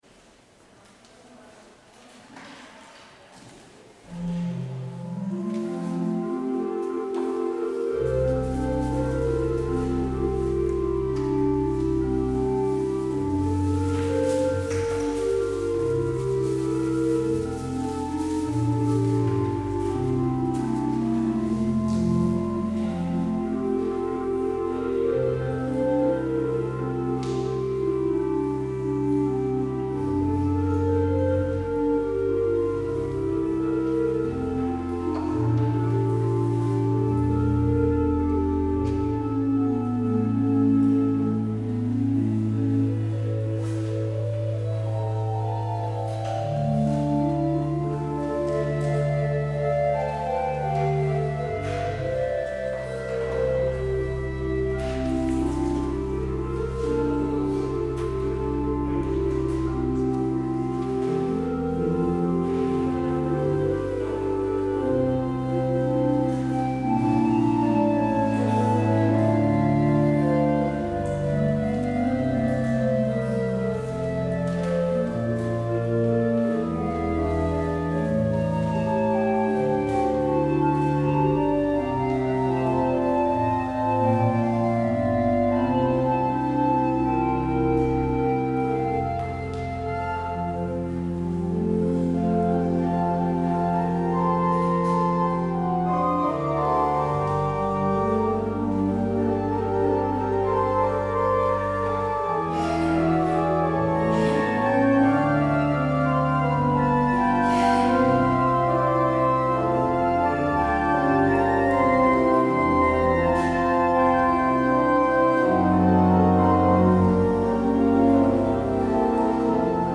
Als gevolg van een storing, is de uitzending t/m de samenzang helaas niet te volgen geweest.